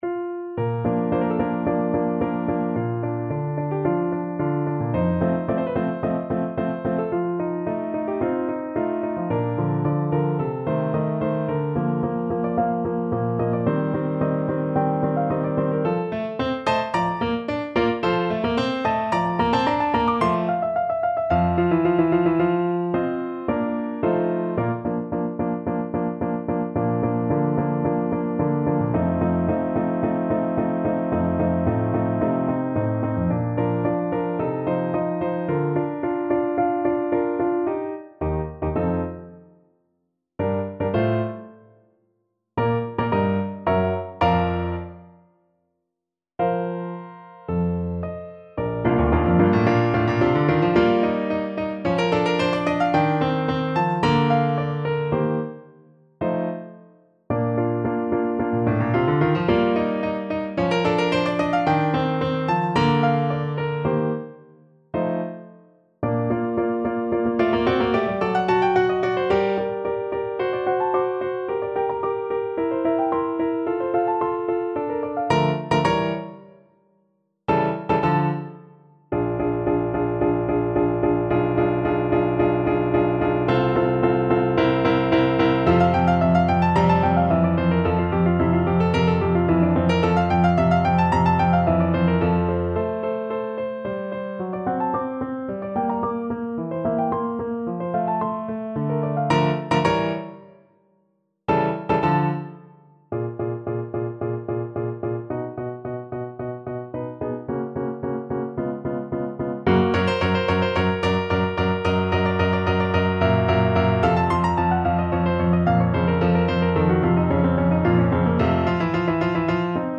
Andante =110
Classical (View more Classical Saxophone Music)